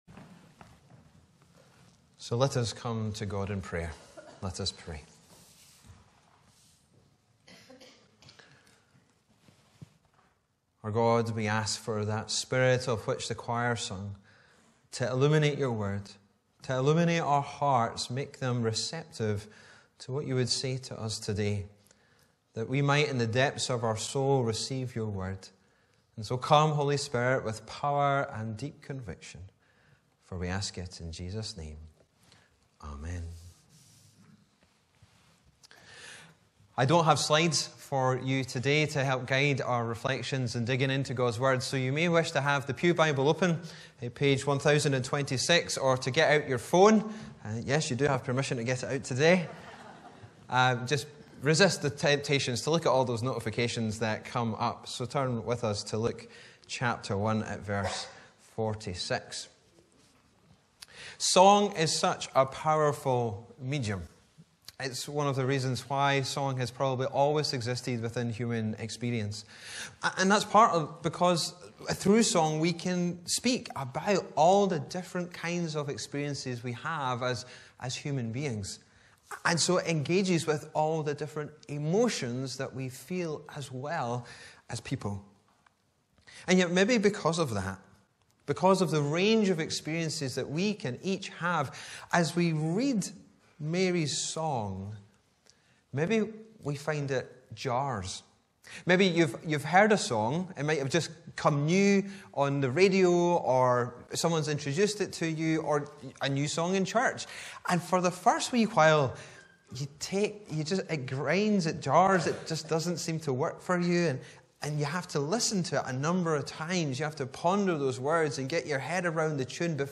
Dec 03, 2023 Mary’s Song MP3 Subscribe to podcast Notes Sermons in this Series Preached on: Sunday 3rd December 2023 The sermon text is available as subtitles in the Youtube video (the accuracy of which is not guaranteed).
Bible references: Luke 1:46-56 Location: Brightons Parish Church